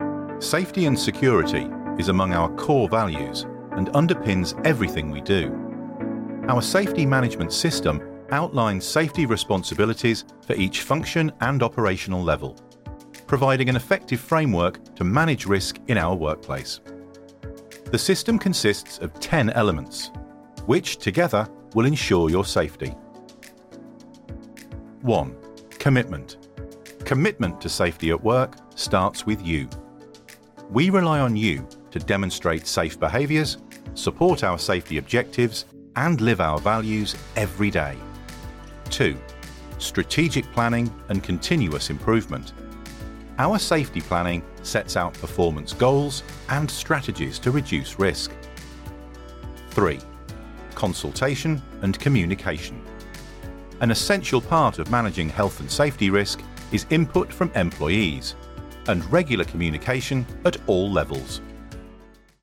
English (British)
Announcements
Mic: SHURE SM7B